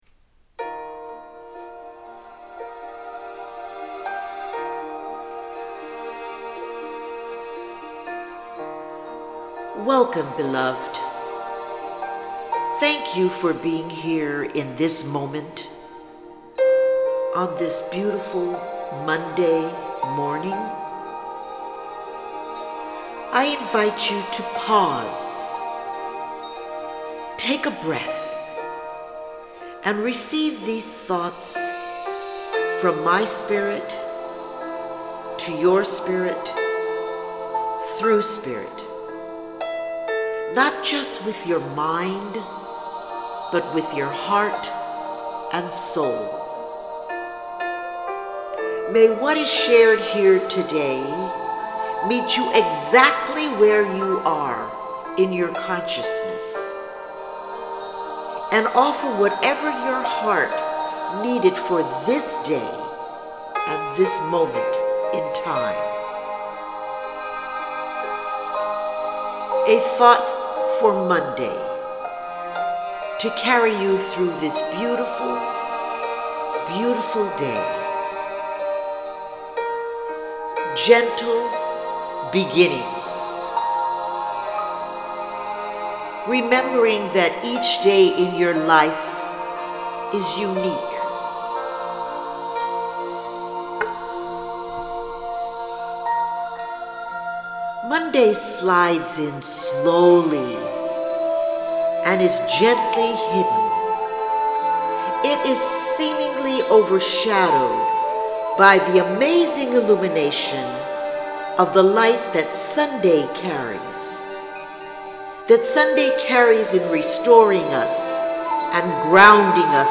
Thank You Jim Brickman for your beautiful music that vibrates through this recording. This is a selection from his album – “Music to Quiet Your Mind and Sooth Your World”.